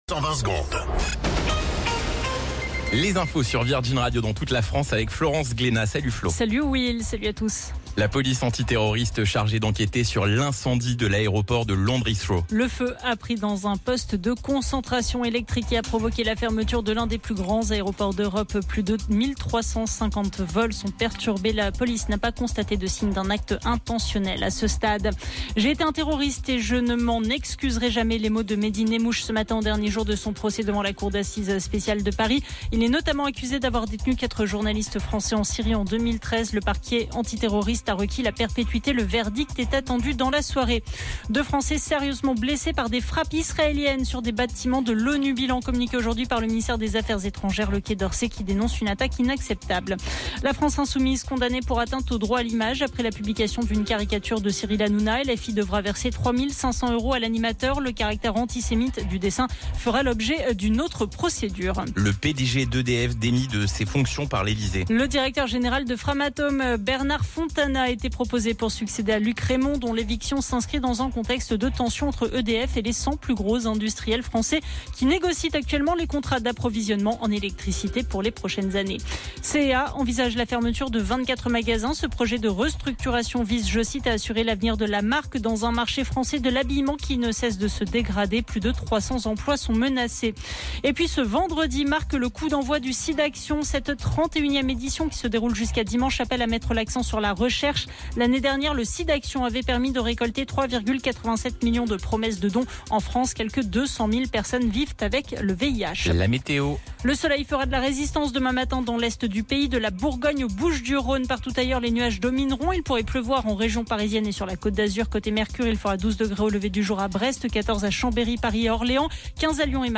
Flash Info National 21 Mars 2025 Du 21/03/2025 à 17h10 .